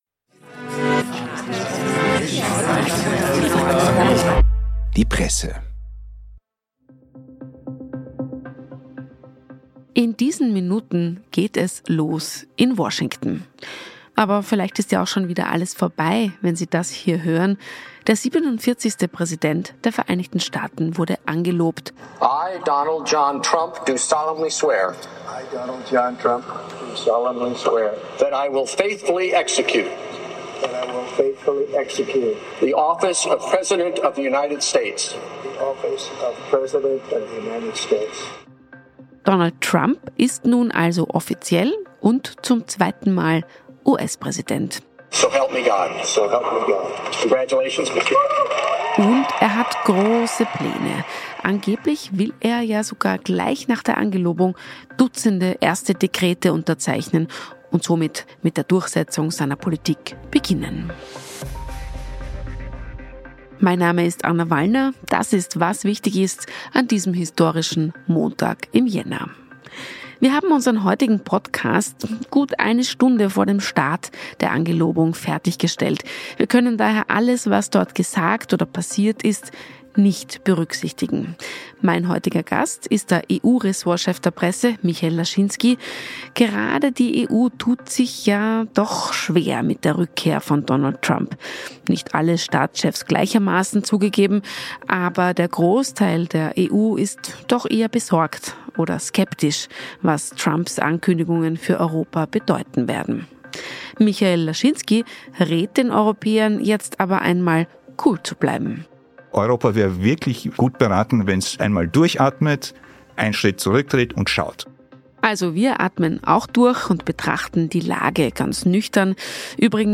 Vier Punkte, die man aus dem Gespräch mit Carlo Masala mitnehmen kann: